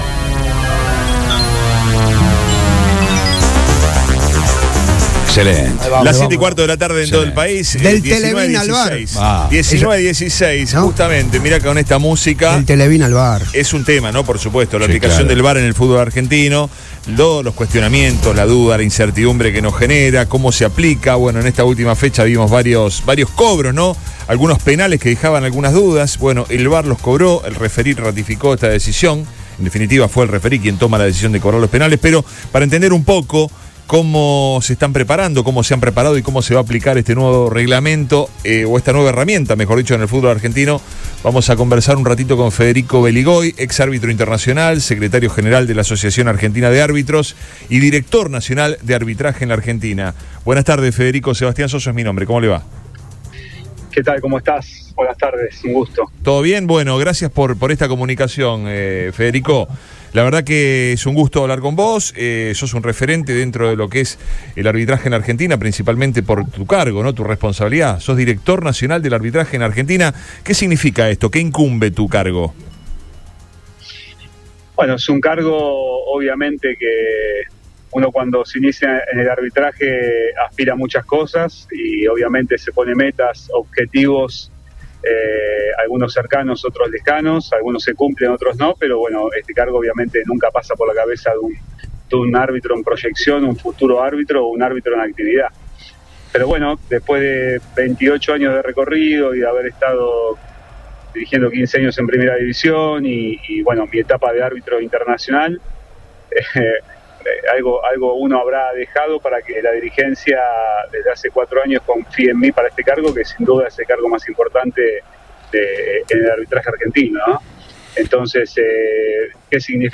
dialogó con Después de Todo por Radio Boing para contar por los pormenores de la utilización del sistema en el torneo doméstico.